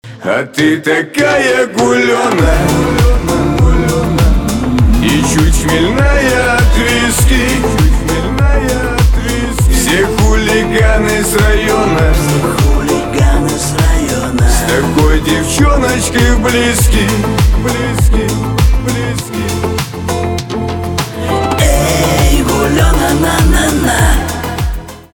шансон
гитара